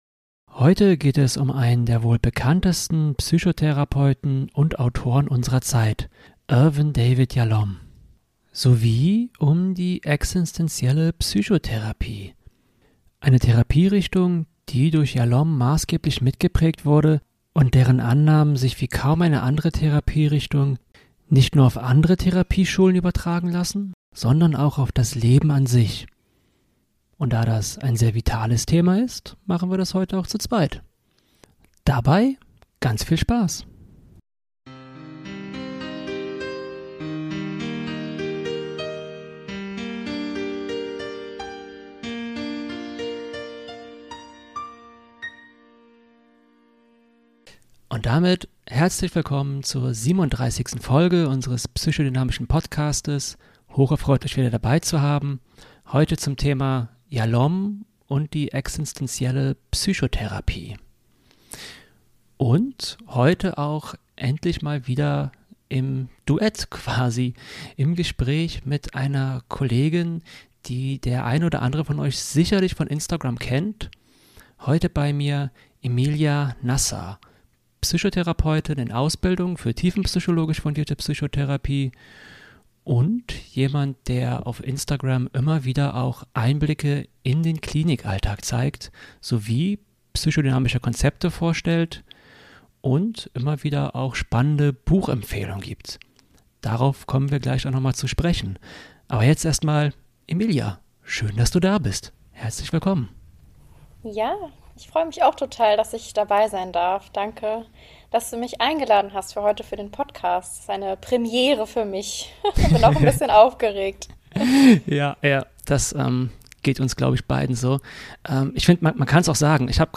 Ich glaube, es wurde noch nie so viel in einer Folge gelacht!